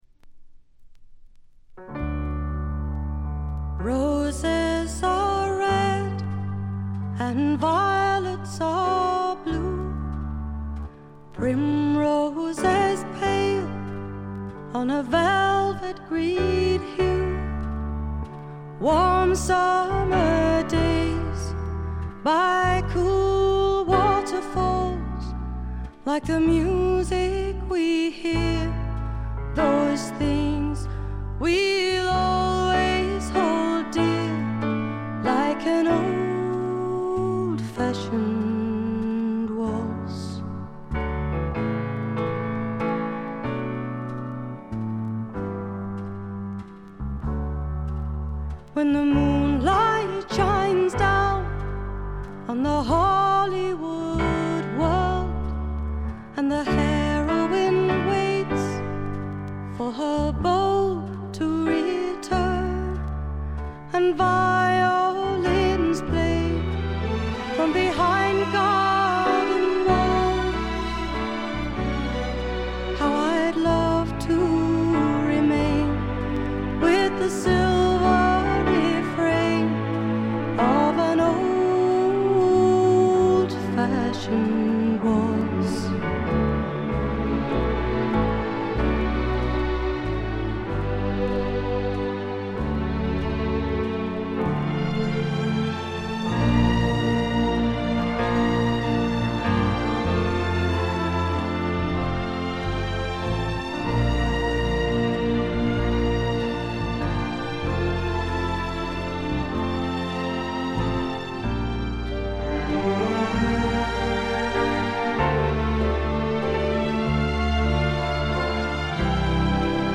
軽微なチリプチ程度。
1stのようないかにもな英国フォークらしさは影を潜め、オールドタイミーなアメリカンミュージック風味が加わってきています。
試聴曲は現品からの取り込み音源です。